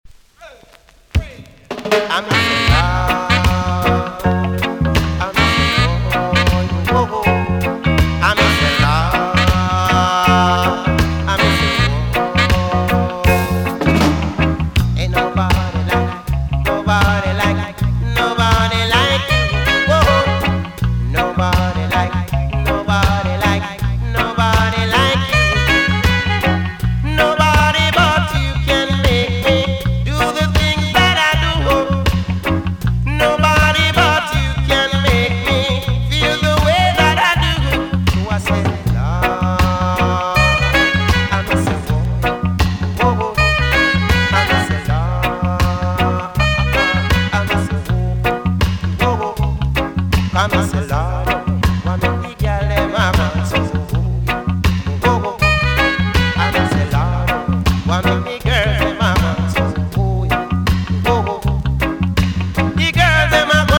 TOP >80'S 90'S DANCEHALL
EX- 音はキレイです。
1983 , 80'S EARLY DANCEHALL TUNE!!